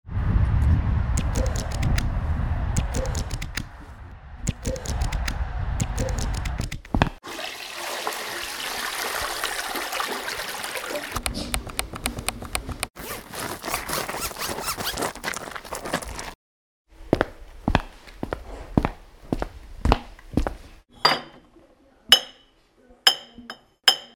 Total Normal Geräuschcollagen
Rund ums Radio war die Redaktion von Total Normal auf Geräusche-Fang und hat die selbst aufgenommenen Geräusche in einer Collage verarbeitet.
Die Schritte auf der Stahlrampe oder das Öffnen des Reisverschlusses nehmen wir im Alltag kaum mehr wahr. Aber als einzelnes Geräusch hört sich das Blätter rascheln oder die Hupe des E-Rollis ziemlich cool an.